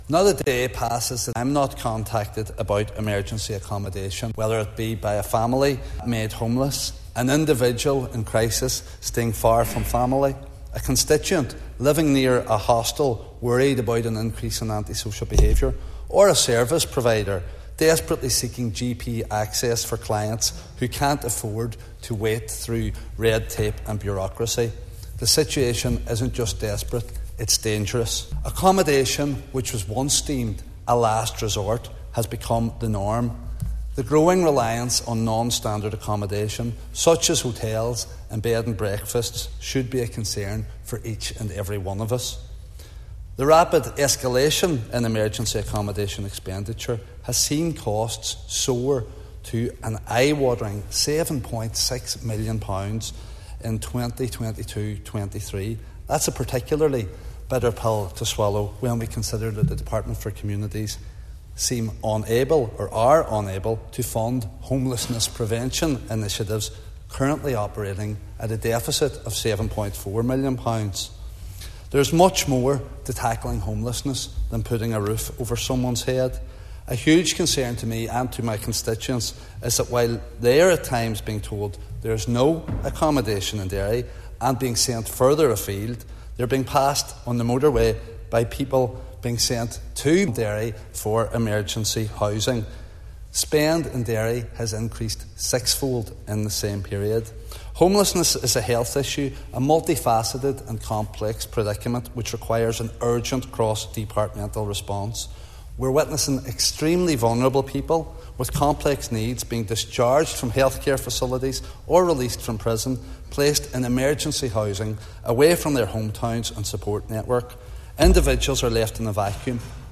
Mark Durkan told the Assembly that at a time when spending is higher than it’s ever been, the quality of accommodation on offer is unacceptable, and what would in the past been regarded as a stop gap measure is now becoming the norm.